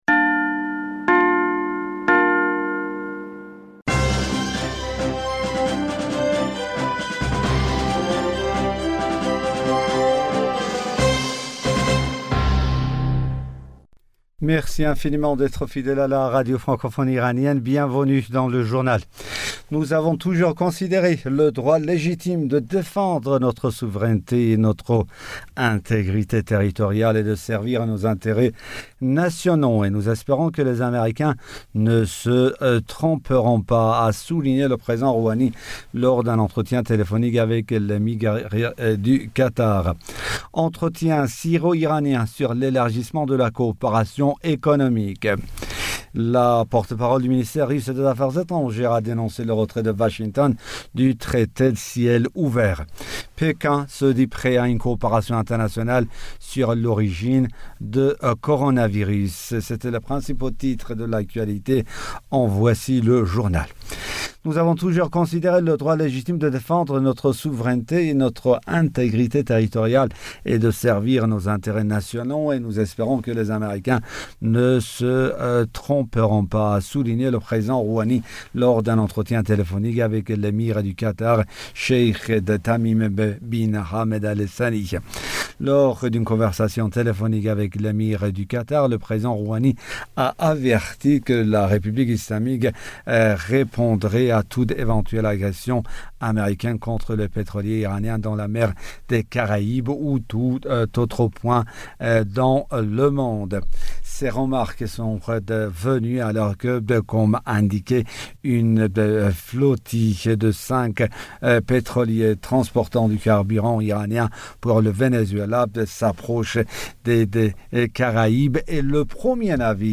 Bulletin d'information du 24 mai 2020